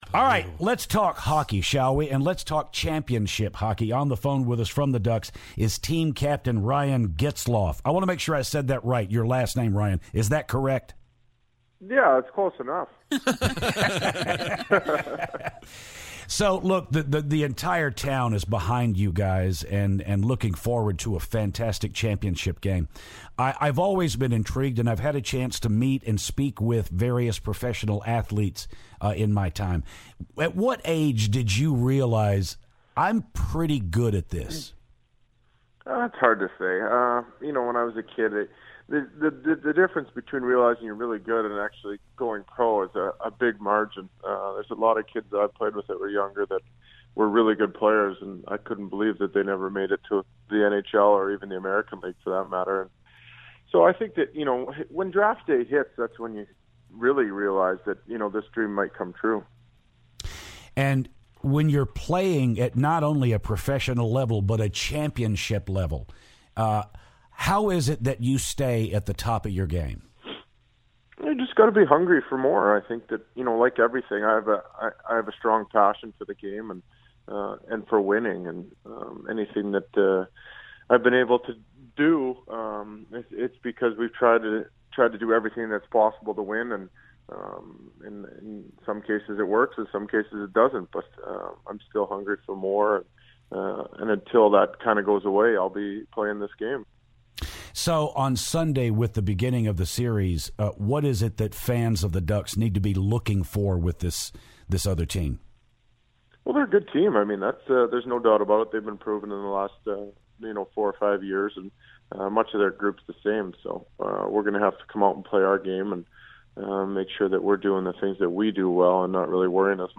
Anaheim Ducks Captain Ryan Getzlaf calls the show!